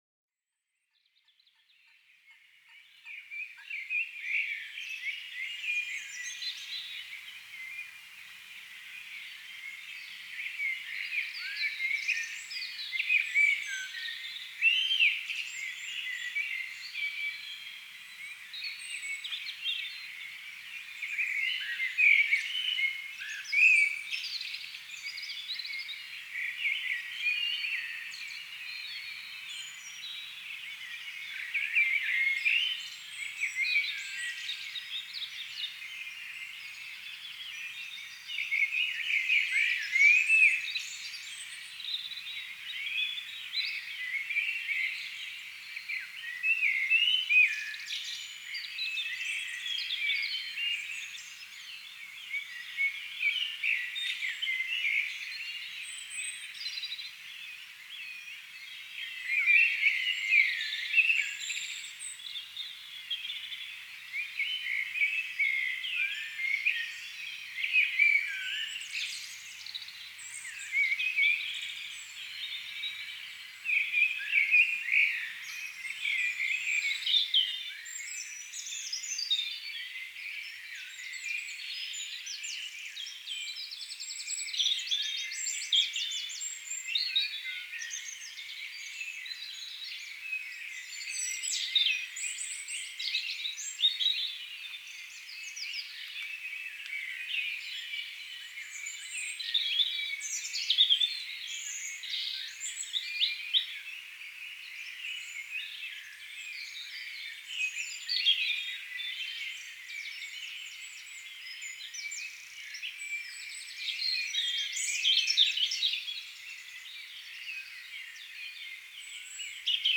Bloemen en vogels in het Vondelpark (Amsterdam).
Birds and flowers of the Vondelpark in Amsterdam.